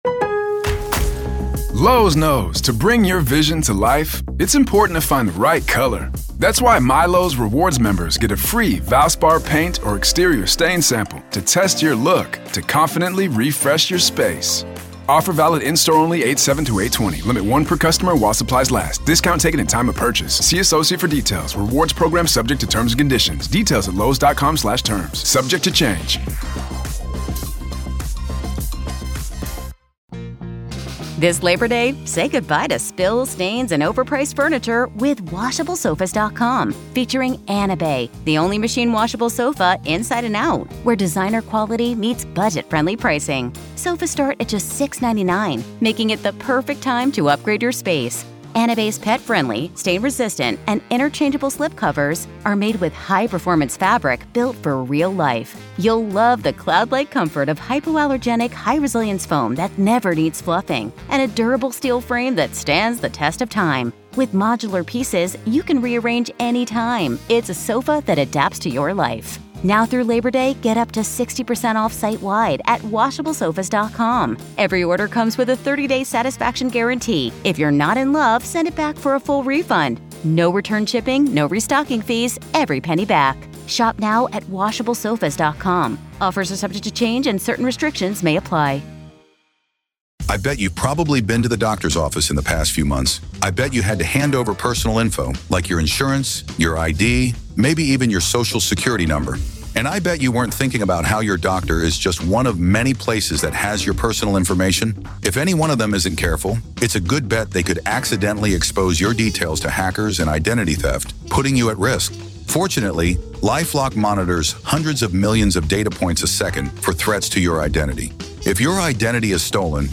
In the Red, White, Salute the Blue Flash-Back Weekend, we pay tribute to those who have dedicated their lives to law enforcement. This event celebrates our favorite interviews from the past, spotlighting the stories, experiences, and insights shared by our esteemed guests from...